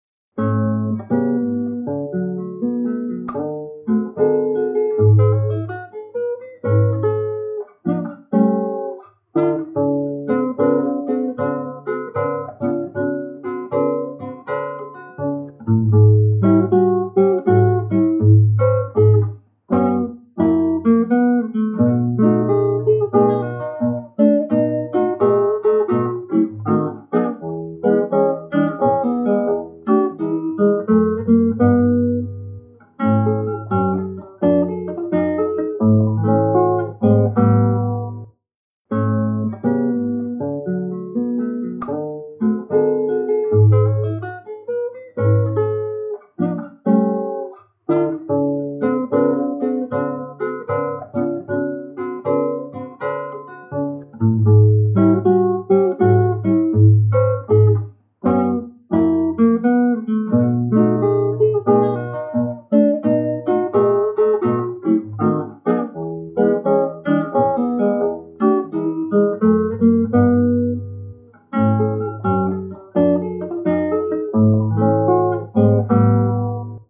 GOSPEL BLUES.mp3